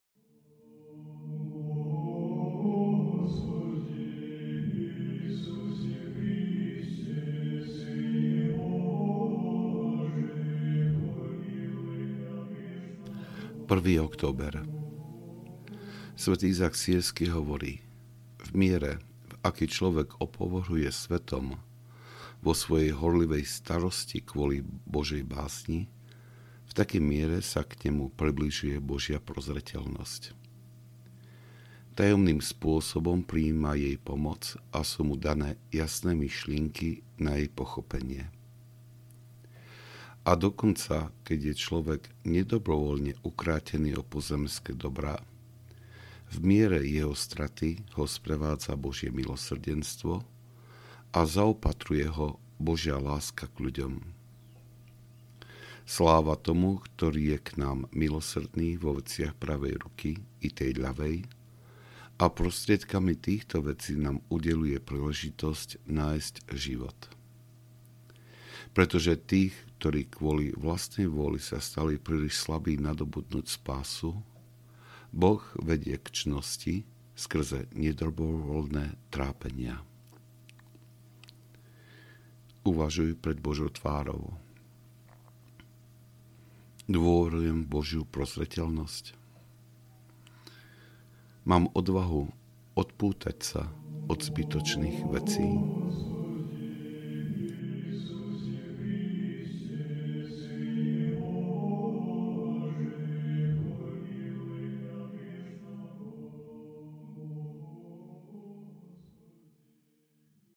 Z múdrosti otcov – Október audiokniha
Ukázka z knihy